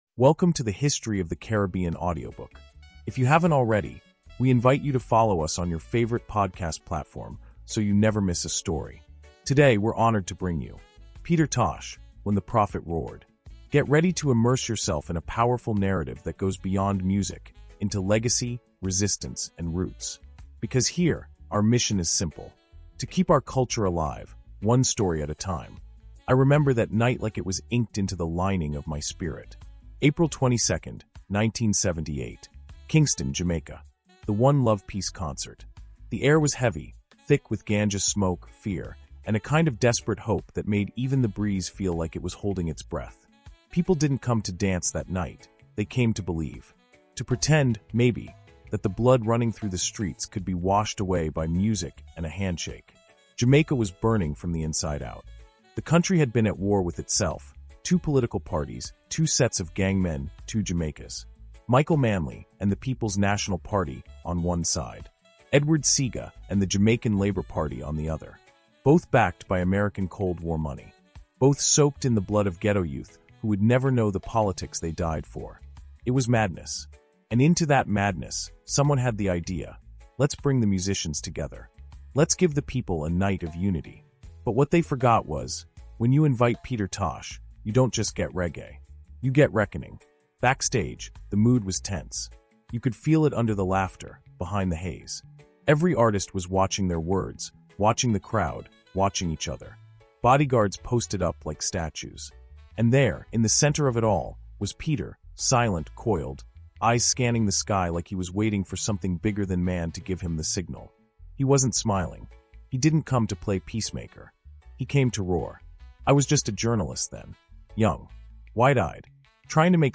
Told from the eyes of a witness, this story reveals the roar behind the rebel and asks what it truly means to speak truth to power.